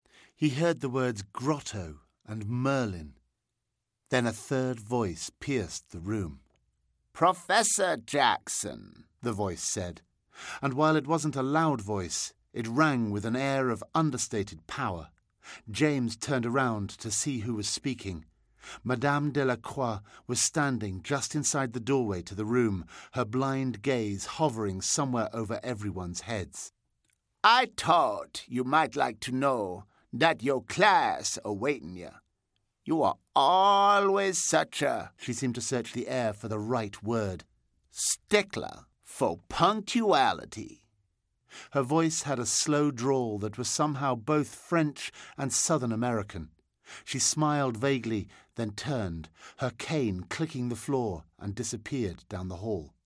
Ever wondered what a Brit sounds like doing a Cajun accent? Take a listen to this sample from James Potter and The Hall Of Elders Crossing!